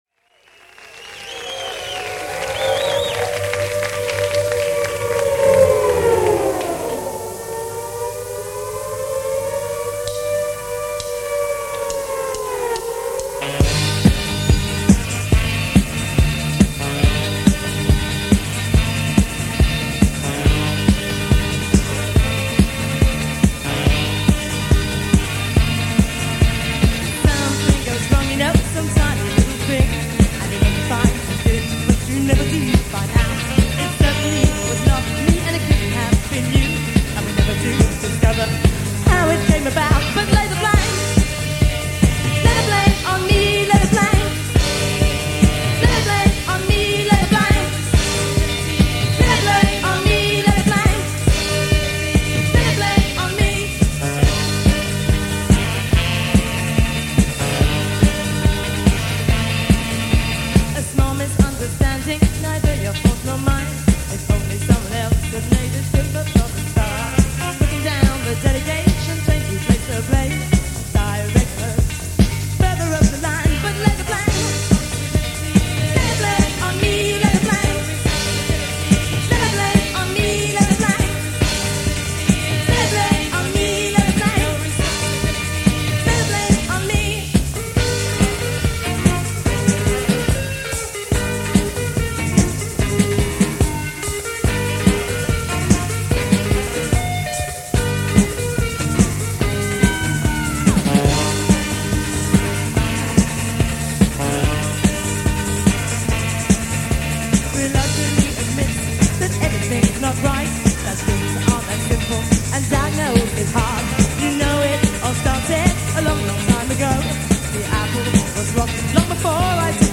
Funk underrated and overlooked.